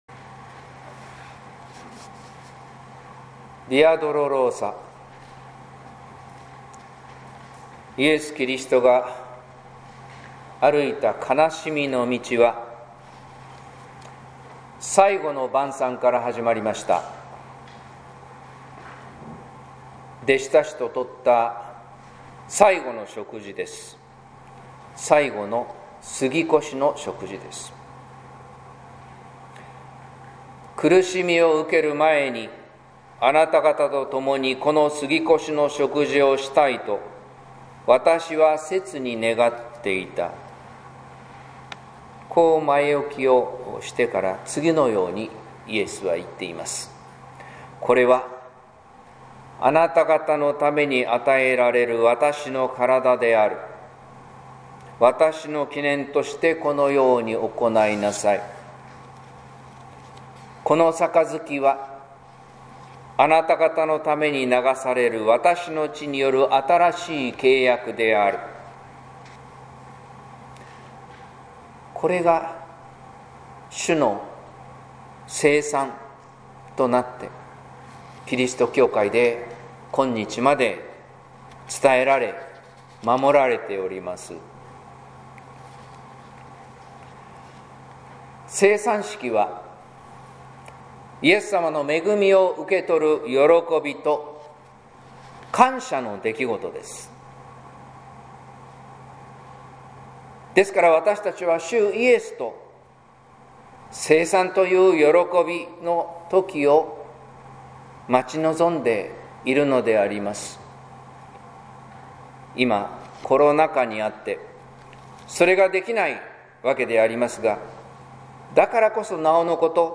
説教「十字架という救い」（音声版）